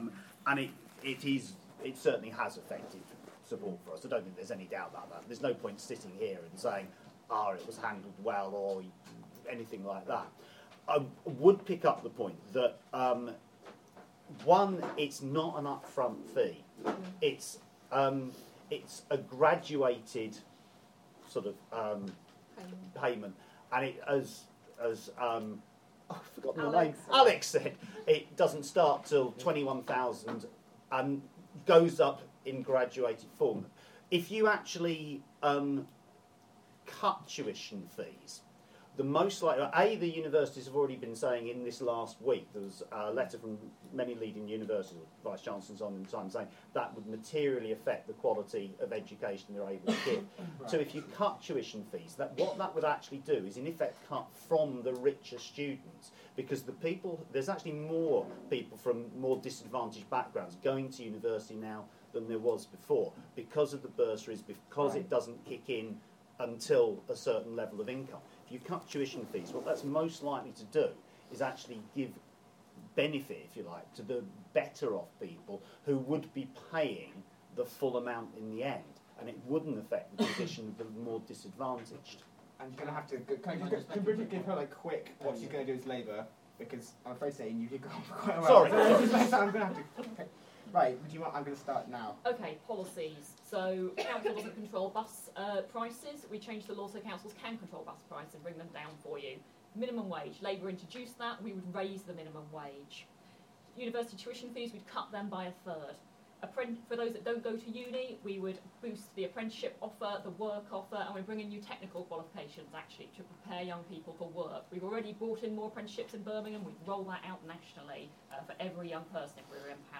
UpRising hustings (2)